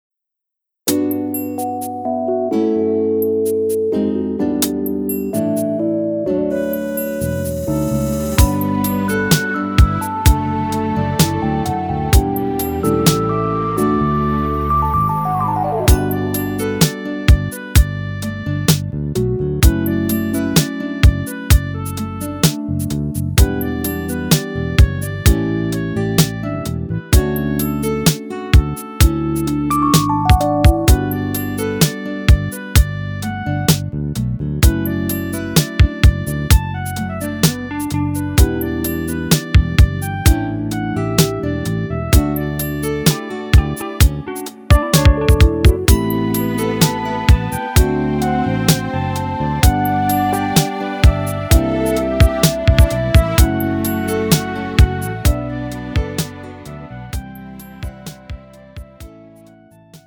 음정 -1키
장르 가요 구분 Pro MR